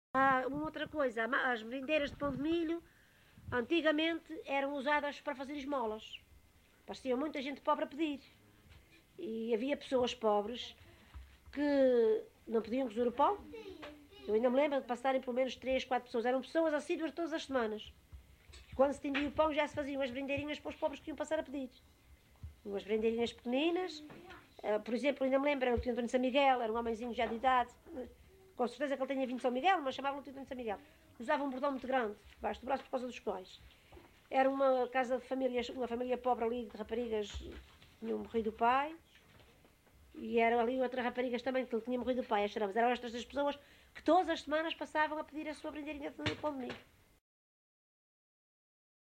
LocalidadeCedros (Horta, Horta)